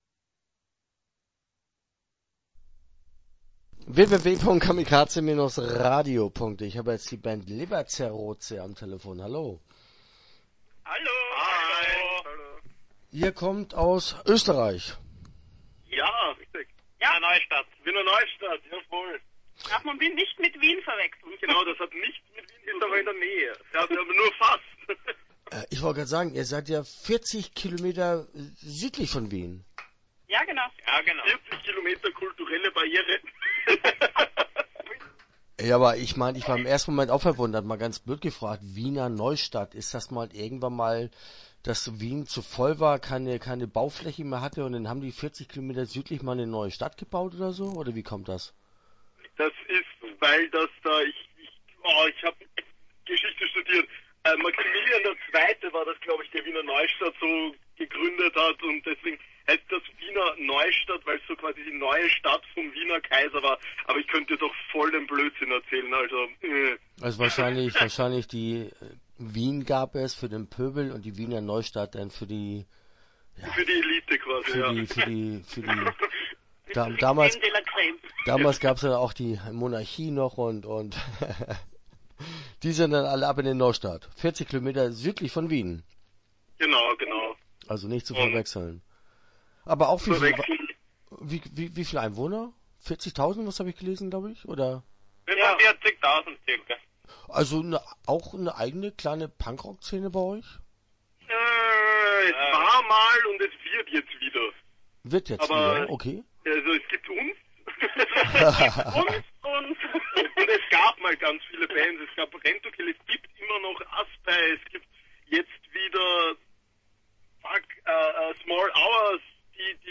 Leberzerroze - Interview Teil 1 (10:46)